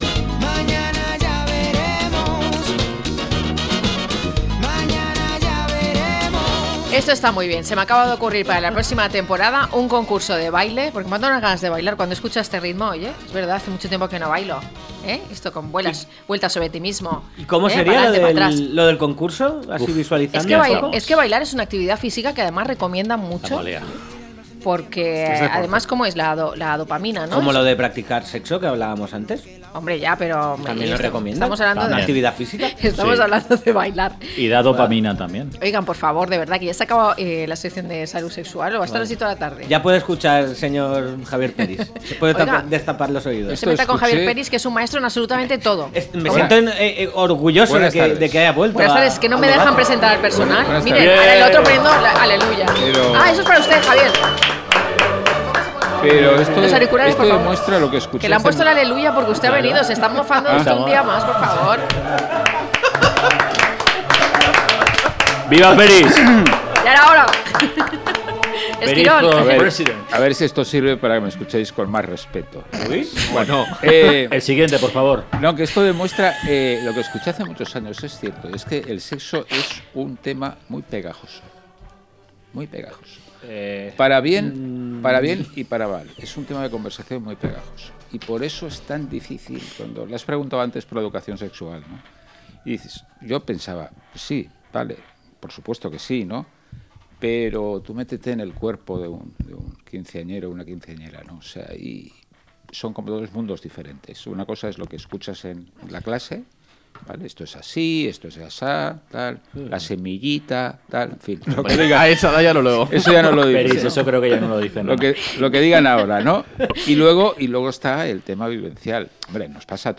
La red de trenes en España, a debate - La tarde con Marina